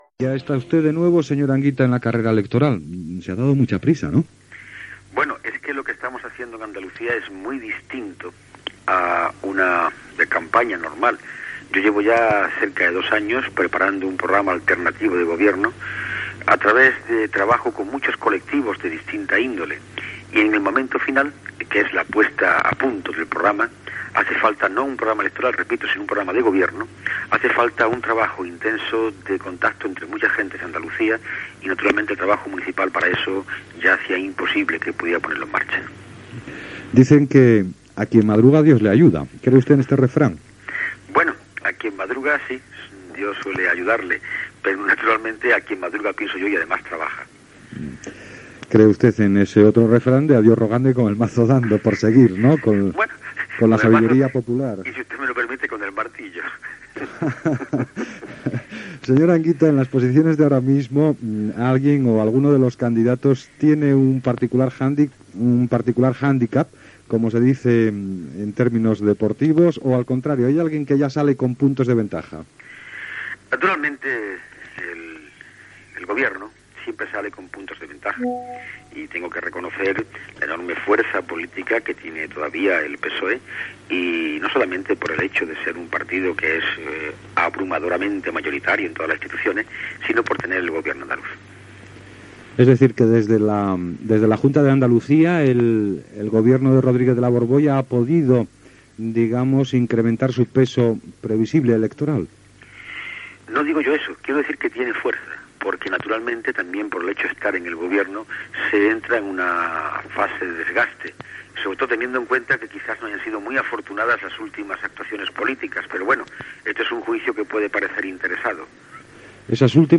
Fragment d'una entrevista telefònica al polític Julio Anguita amb motiu de les eleccions autonòmiques andaluses
Informatiu